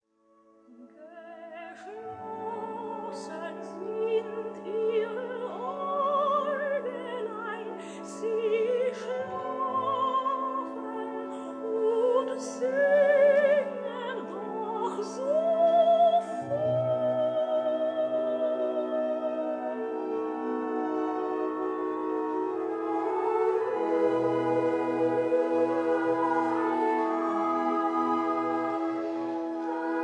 soprano
contralto
baritone
The Choirs of Loughton High School